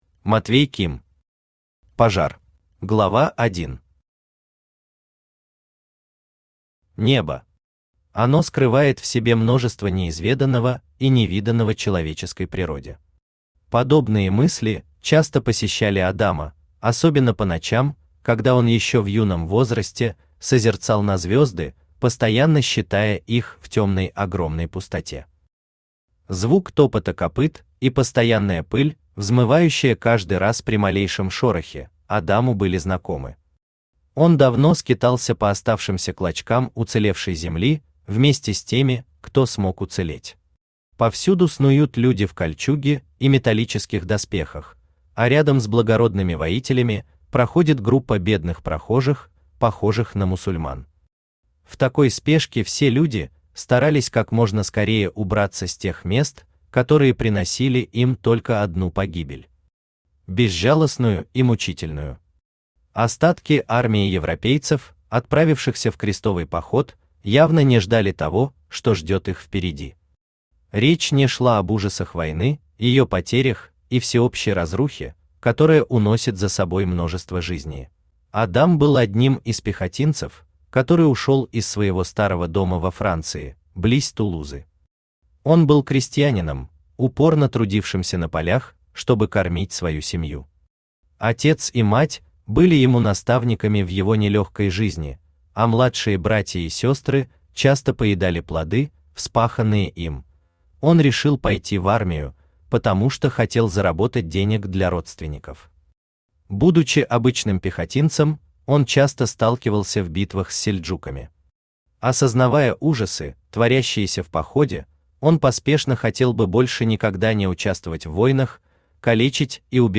Аудиокнига Пожар | Библиотека аудиокниг
Aудиокнига Пожар Автор Матвей Олегович Ким Читает аудиокнигу Авточтец ЛитРес.